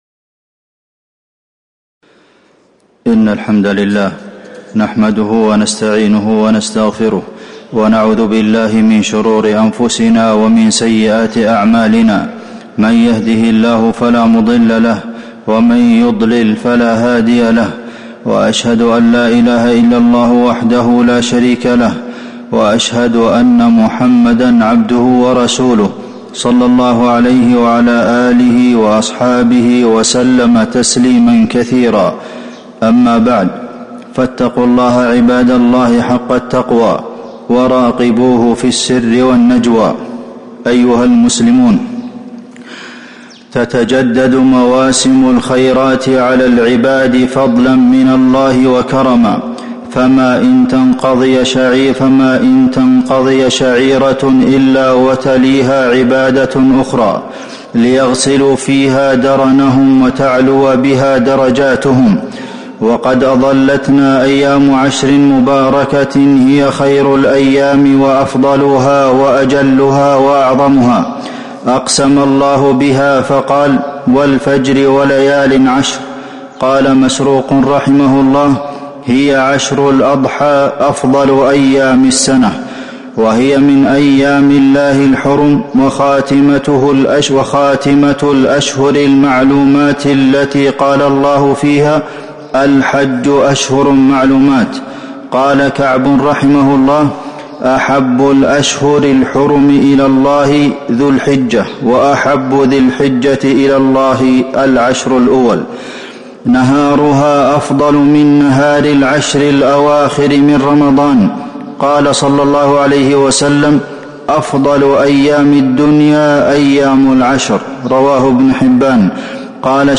تاريخ النشر ٥ ذو الحجة ١٤٤٤ هـ المكان: المسجد النبوي الشيخ: فضيلة الشيخ د. عبدالمحسن بن محمد القاسم فضيلة الشيخ د. عبدالمحسن بن محمد القاسم خير أيام الدنيا